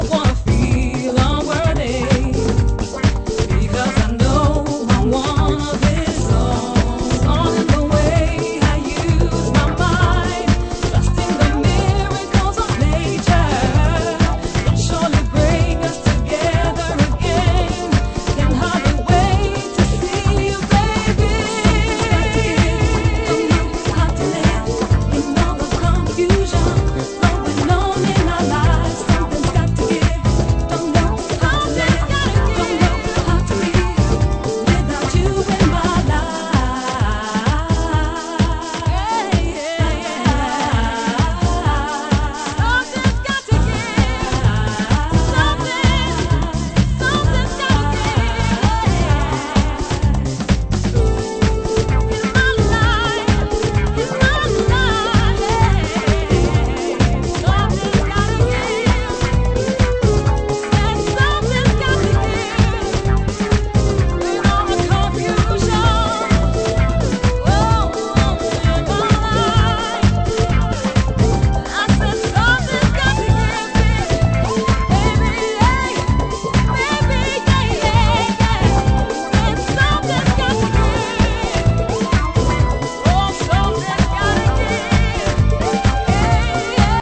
(Main Vocal Version)